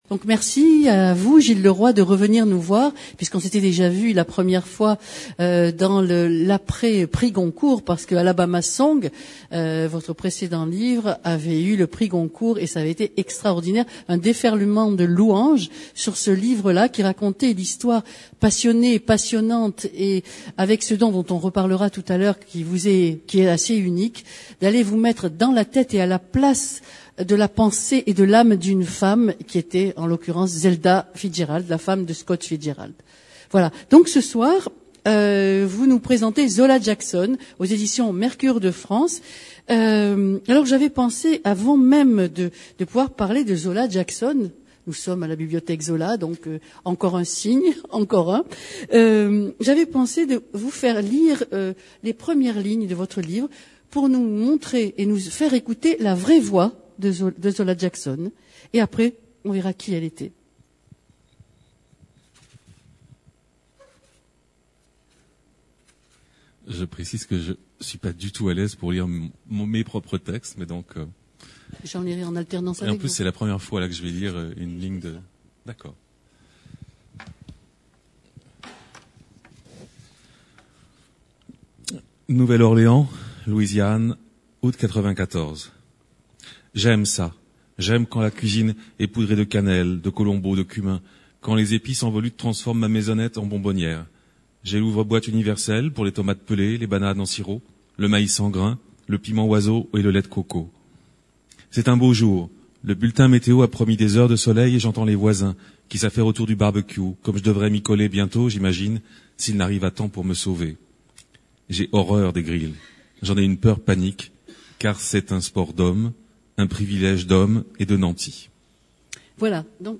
Personne interviewée' en grand format /5 0 avis Rencontre avec Gilles Leroy Date de publication ou de production : 2010 Leroy, Gilles.
Rencontre littéraire